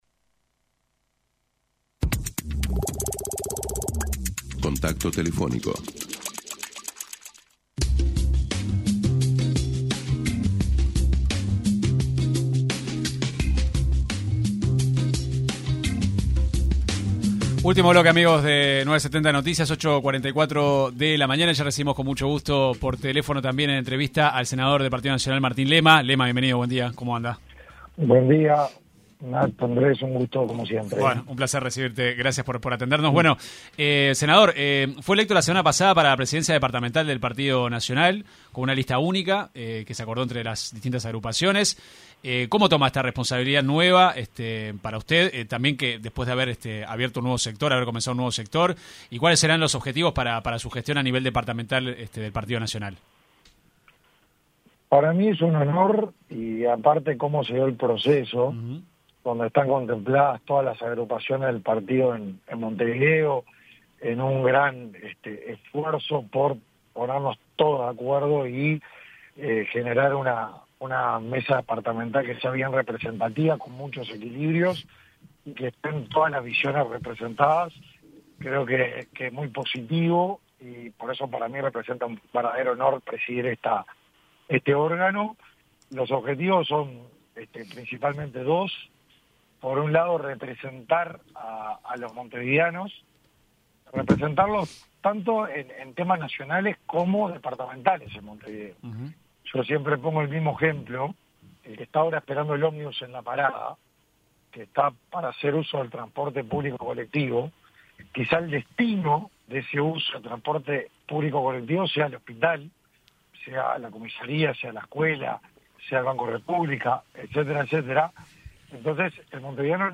El senador nacionalista y flamante presidente de la Comisión Departamental de Montevideo del Partido Nacional, criticó en una entrevista con 970 Noticias, la gestión que lleva adelante el intendente Mario Bergara en la capital del país.